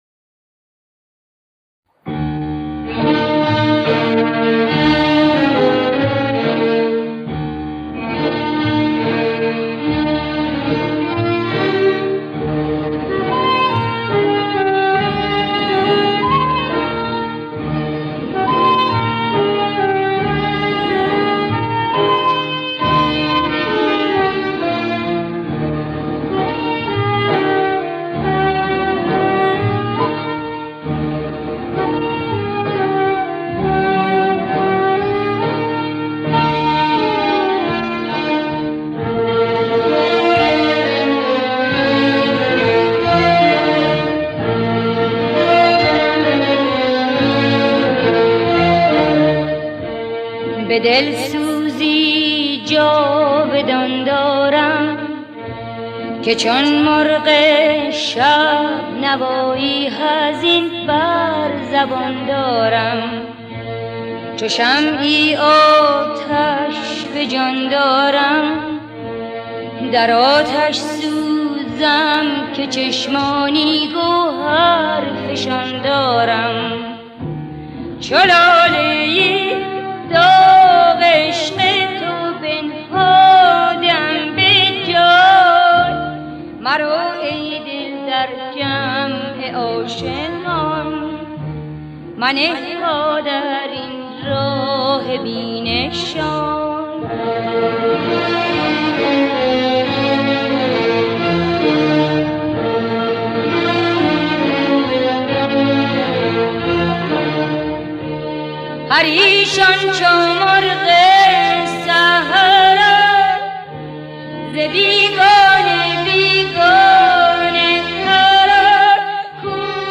دستگاه: بیات ترک